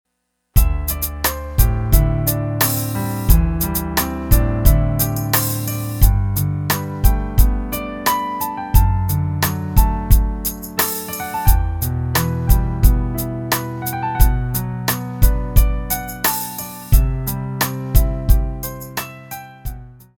G on the D String, Pinky A, Augmentation/Diminution